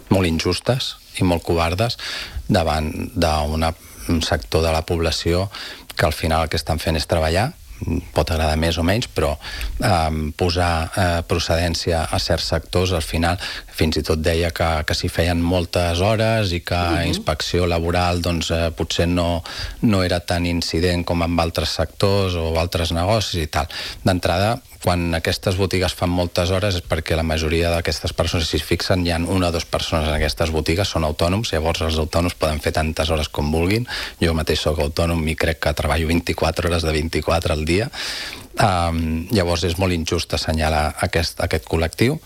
Així de contundent s’ha mostrat Enric Gómez, regidor portaveu d’Estimem Calella, en una entrevista aquest dijous al matinal de RCT, on ha carregat contra la gestió del govern municipal en matèria de promoció de ciutat.